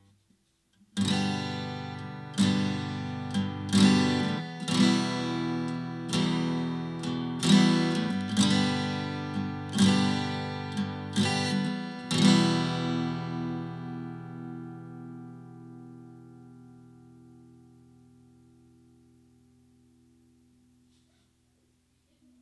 Type: Dynamic Cardioid
Acoustic GTR Strumming w/pick – 30 degrees 12 inches from 12th fret